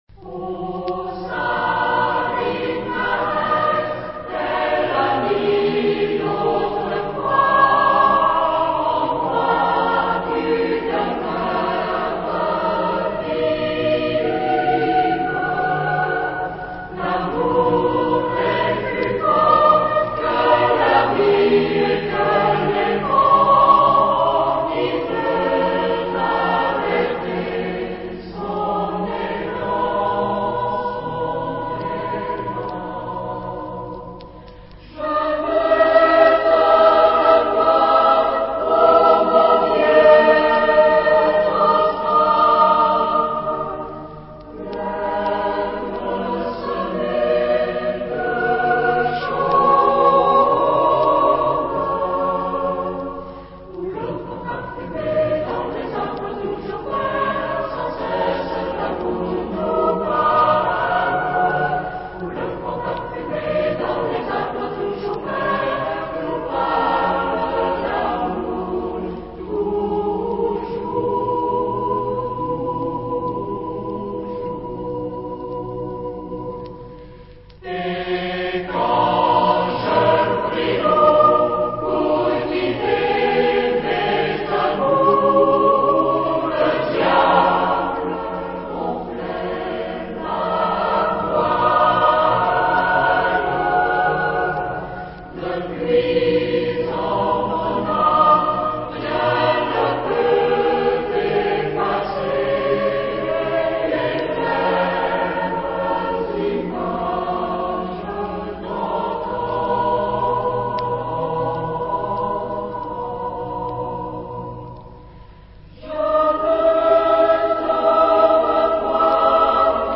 Genre-Style-Forme : Populaire ; Chanson ; Profane
Type de choeur : SATB  (4 voix mixtes )
Tonalité : la majeur
Origine : Afrique du Sud